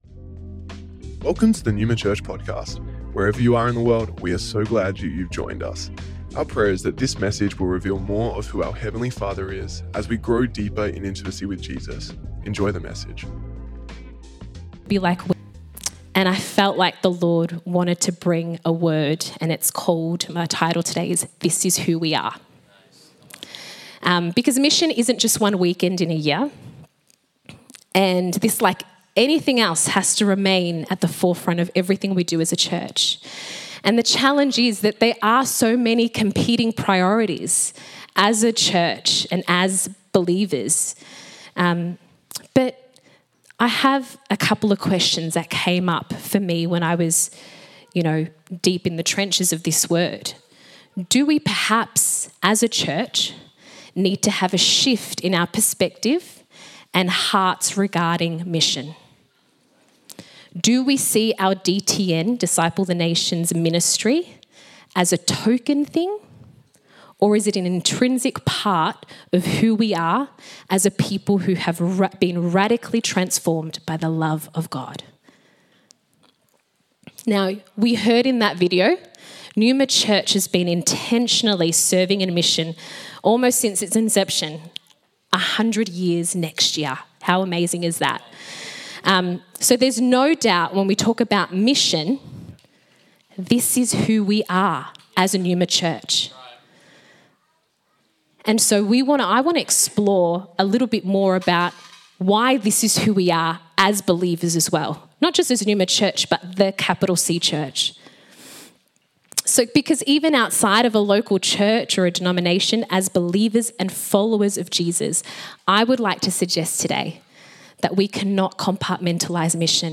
Originally recorded at Neuma Melbourne West